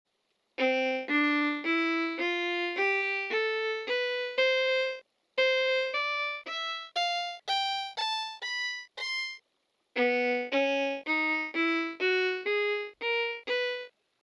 Bardic_Fiddle_Sound.mp3